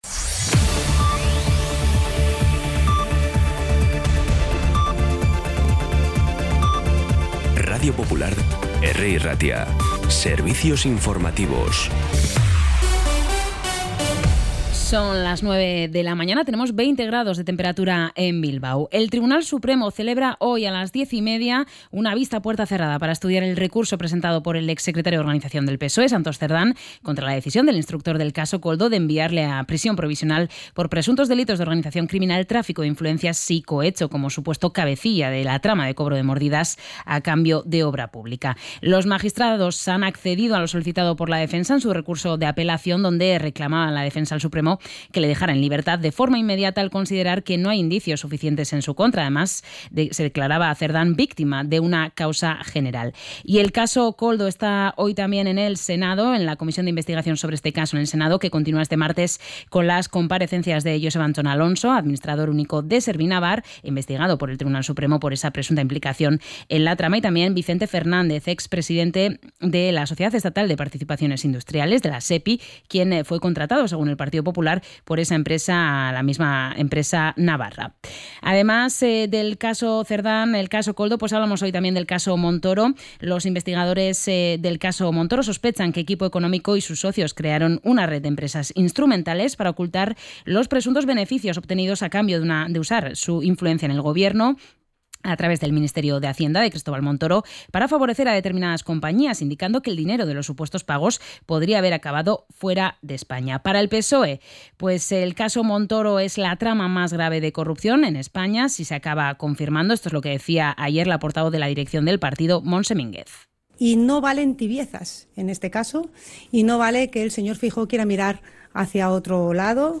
Información y actualidad desde las 9 h de la mañana